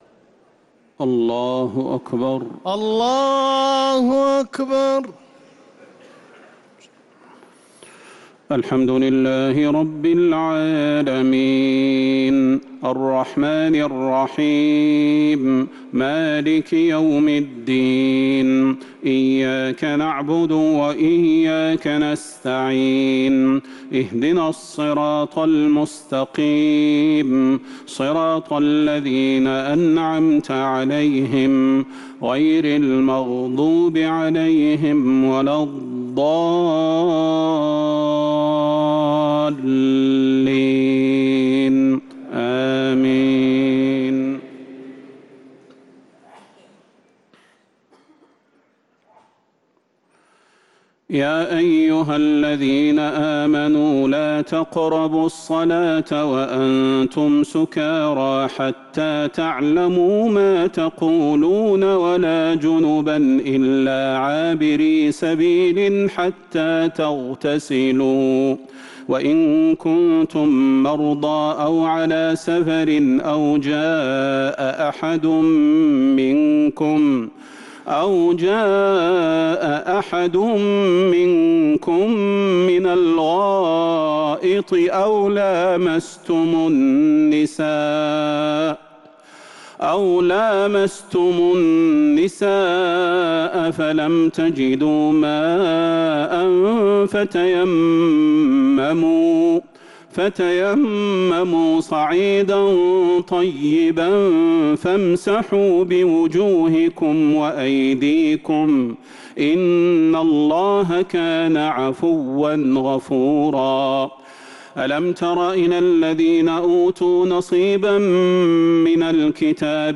تراويح ليلة 6 رمضان 1447هـ من سورة النساء (43-87) | Taraweeh 6th night Ramadan 1447H Surat An-Nisaa > تراويح الحرم النبوي عام 1447 🕌 > التراويح - تلاوات الحرمين